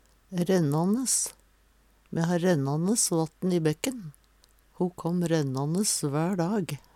rennanes - Numedalsmål (en-US)